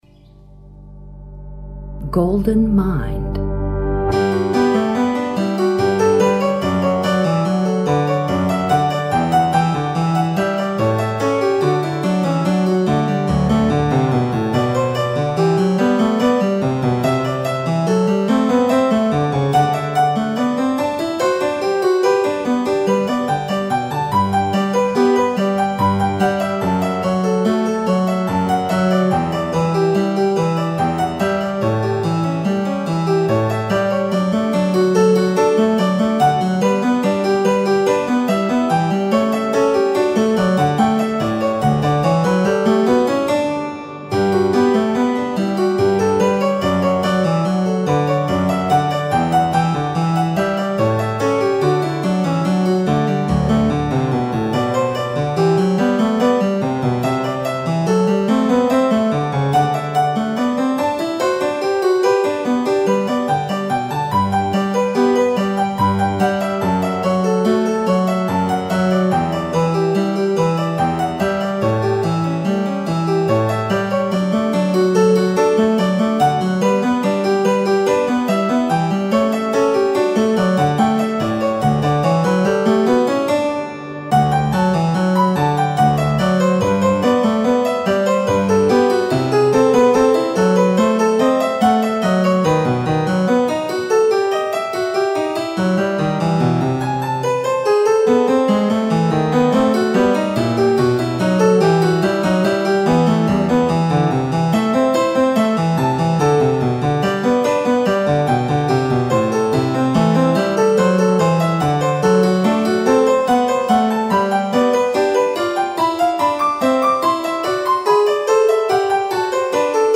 Живая интерпретация
для клавесина